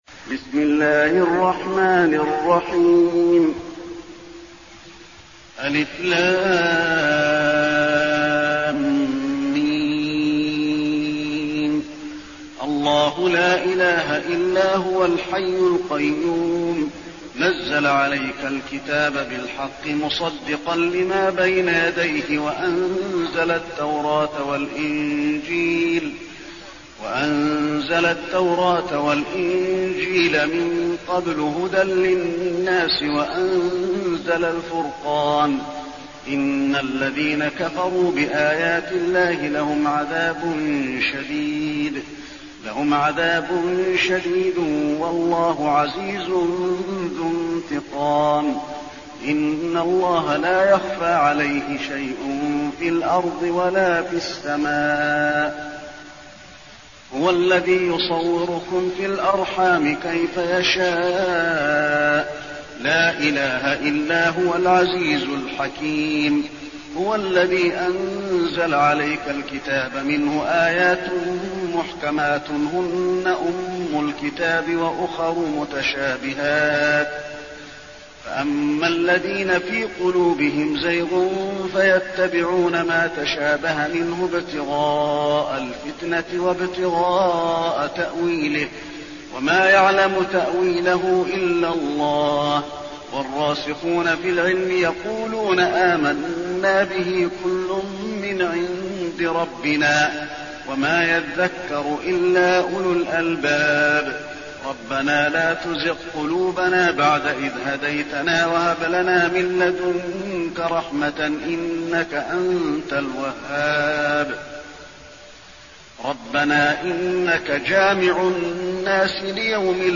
المكان: المسجد النبوي آل عمران